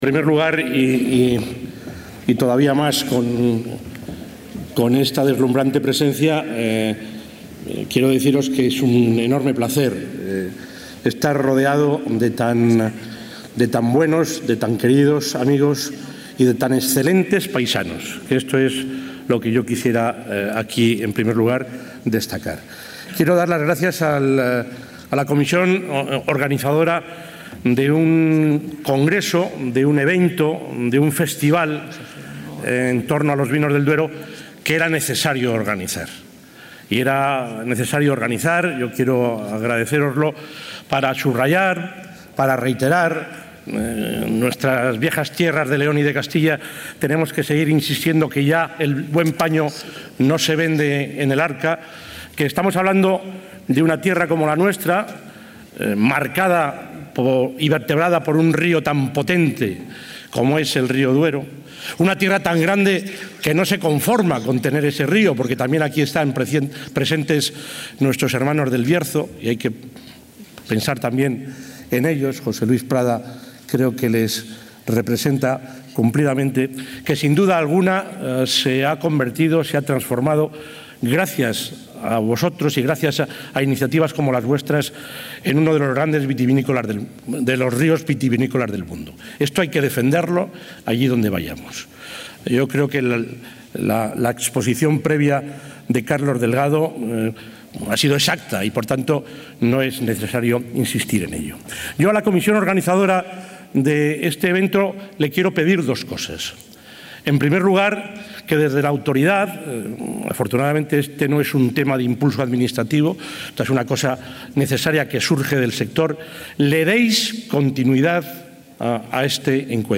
Intervención del presidente de la Junta.
El presidente de la Junta de Castilla y León ha intervenido hoy en el acto de homenaje a los grandes pioneros del vino en Castilla y León celebrado durante la primera edición del Congreso ‘Duero Internacional Wine Fest’, que se desarrolla estos días en el Fórum Evolución de la capital burgalesa.